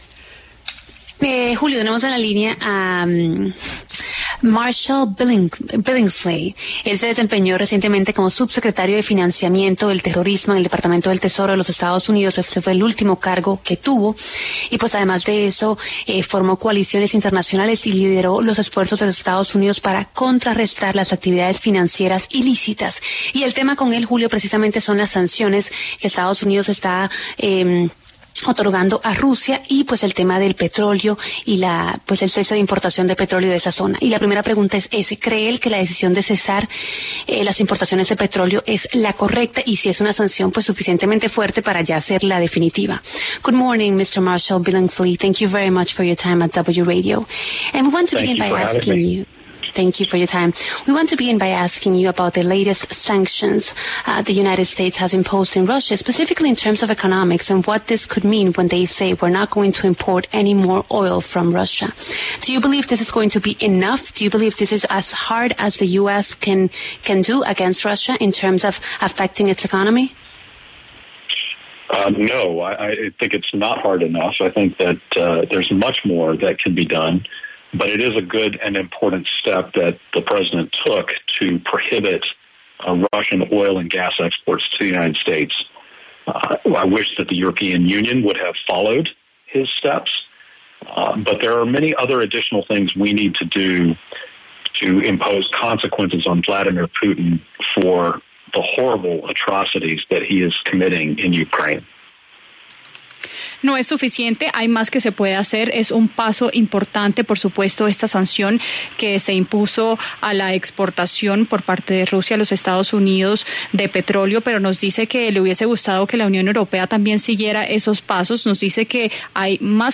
En el encabezado escuche la entrevista completa con Marshall S. Billingslea, quien se desempeñó como subsecretario de financiamiento del terrorismo en el Departamento del Tesoro de Estados Unidos.